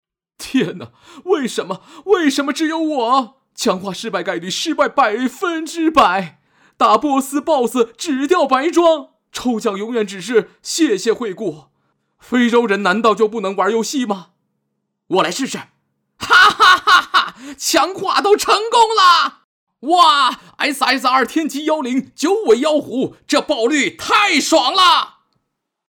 C男171号 | 声腾文化传媒
【角色】夸张.mp3